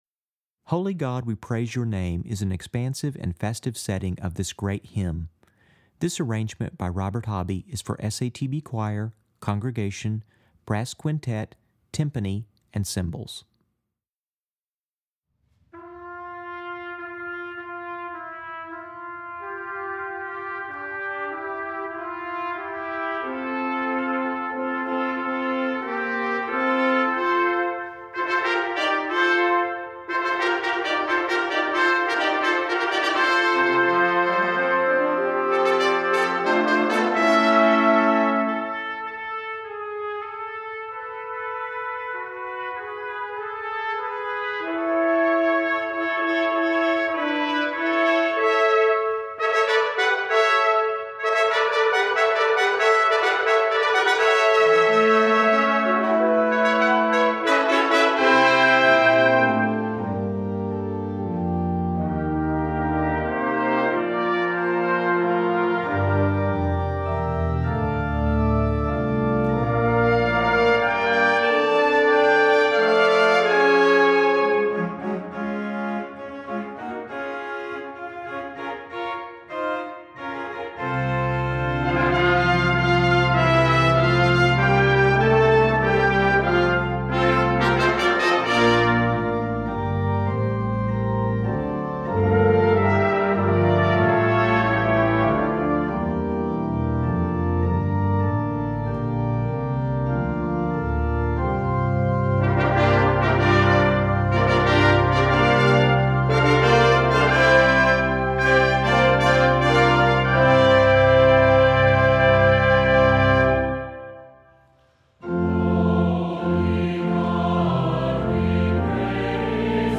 Voicing: SATB and Congregation